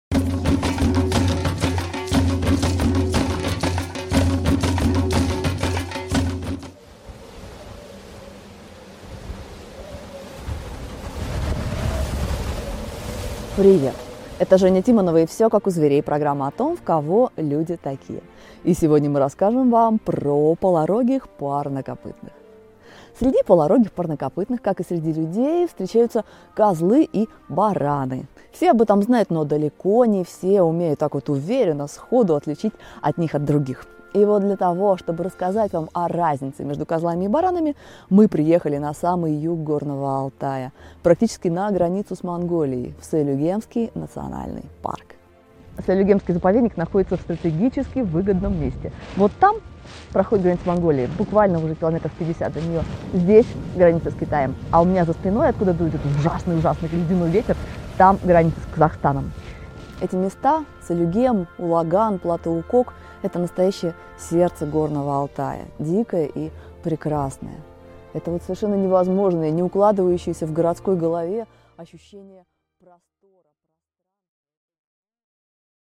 Аудиокнига Козёл или баран?
Прослушать и бесплатно скачать фрагмент аудиокниги